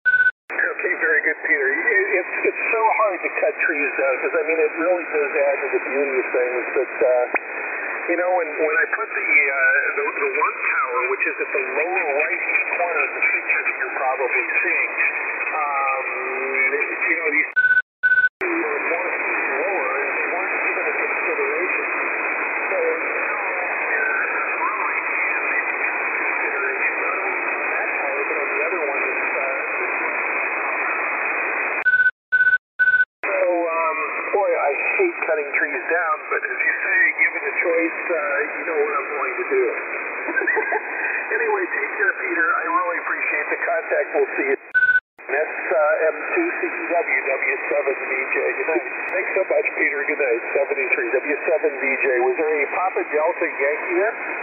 I have marked the start of the inverted L with one beep, the dipole with two beeps, and the Steppir with three.
The inverted L is receiving almost as clearly as the 3 element at over twice the height. I’m sure the beam would be showing a higher signal strength, but in terms of clarity there is not much to choose between them.